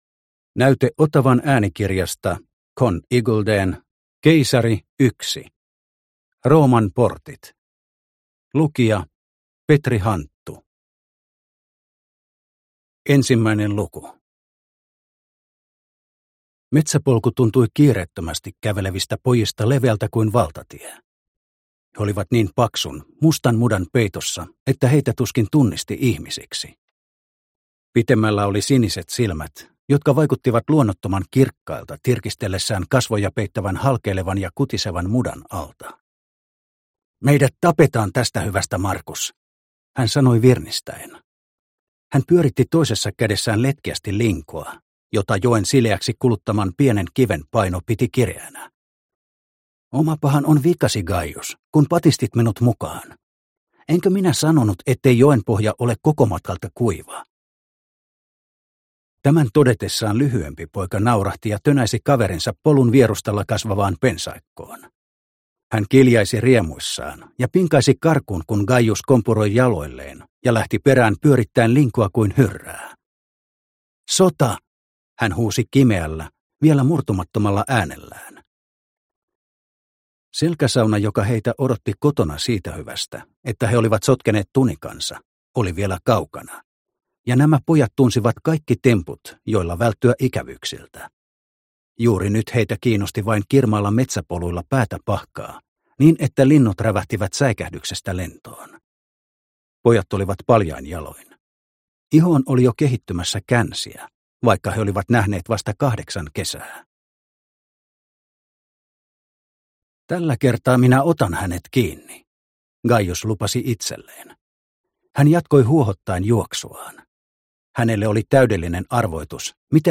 Keisari I. Rooman portit – Ljudbok – Laddas ner